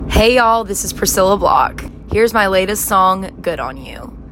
LINER Priscilla Block (Good On You) 3